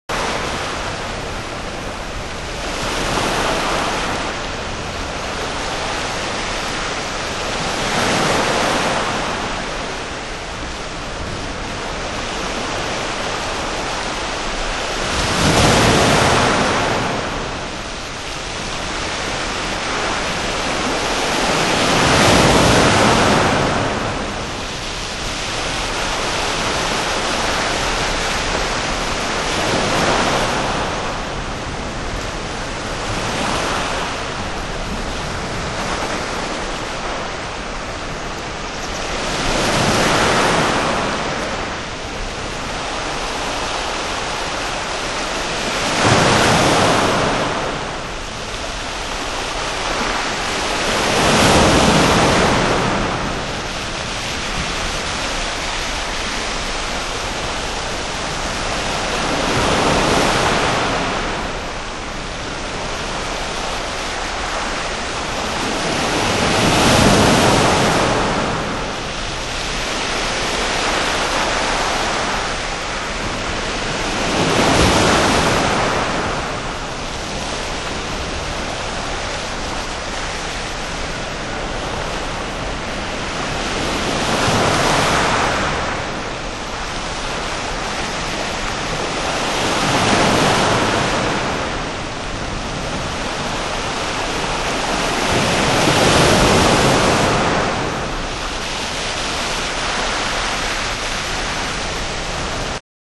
A 10 Minutes Of Thunderstorm   B1
10 Minutes Of Waves   B2 Artificial Winds   B3 Dripping Water 　　 　　　盤質：少しチリパチノイズ有　　ジャケ：薄汚れ有/取り出し口にヨレ有